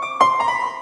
PIANO TICKLE.wav